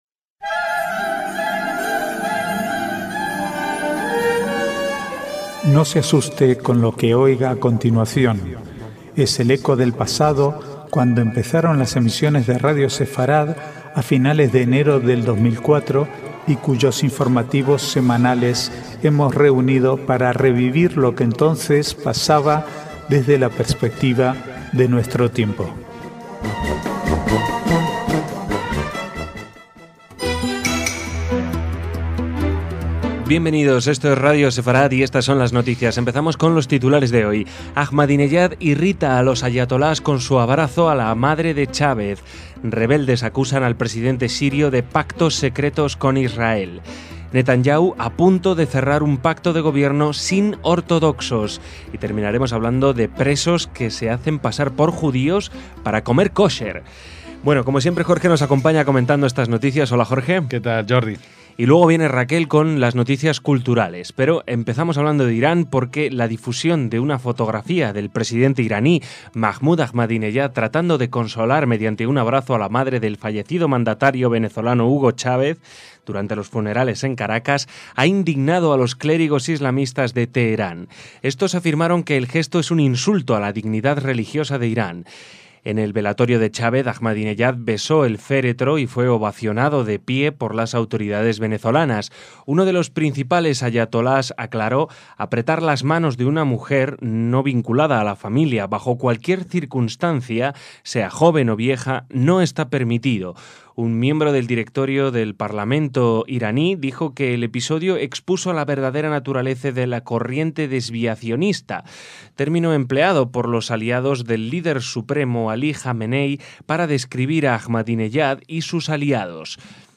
Archivo de noticias del 12 al 15/3/2013